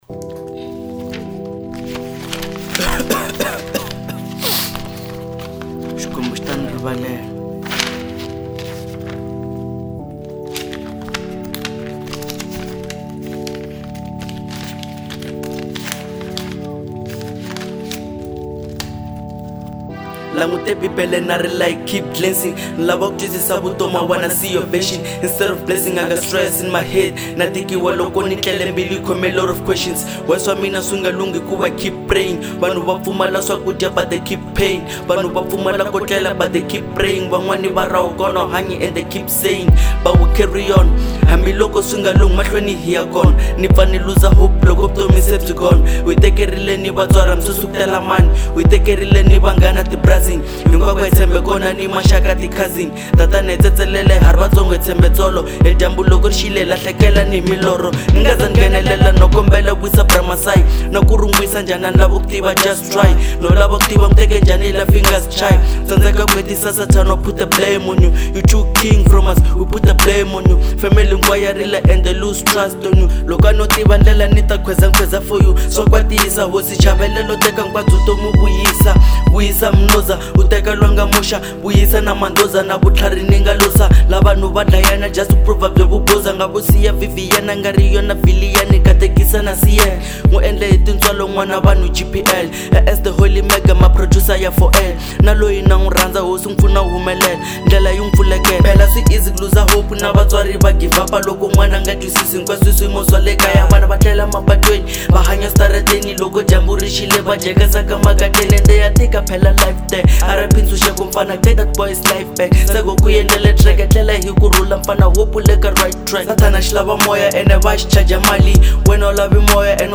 Genre : Acapella